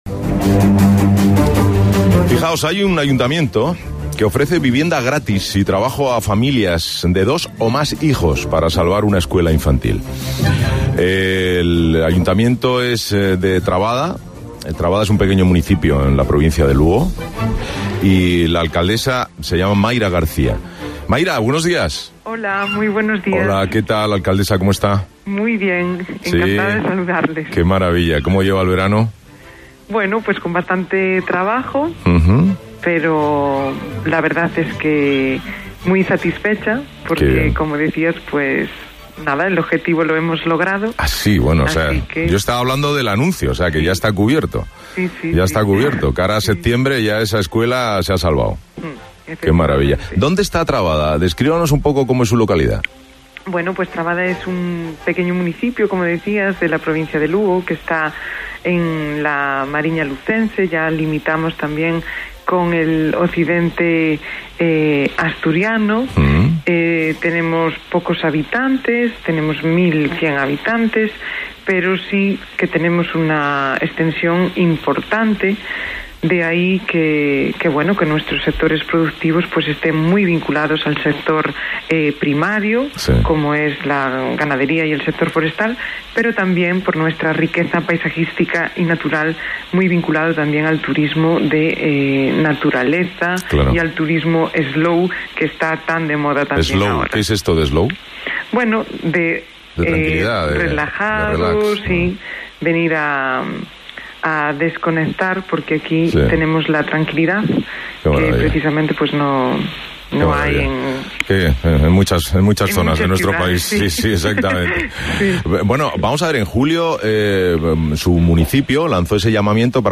Mayra García, alcaldesa de Trabada, entrevistada